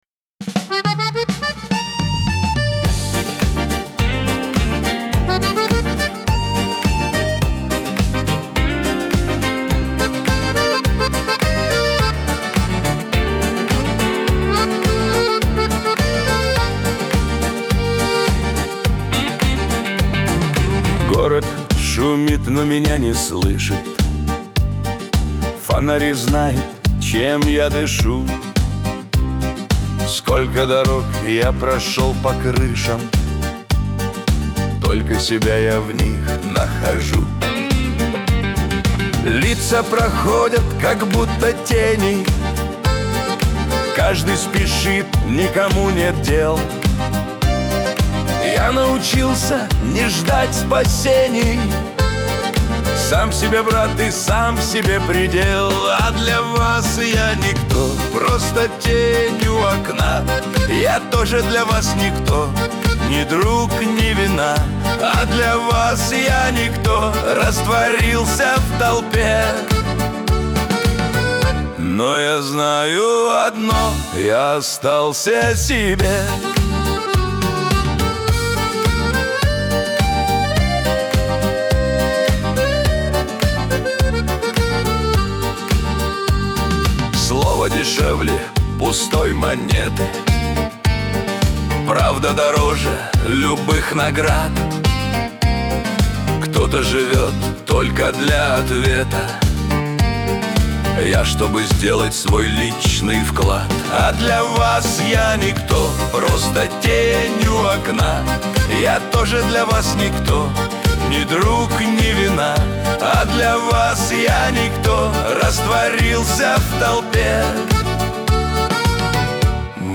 грусть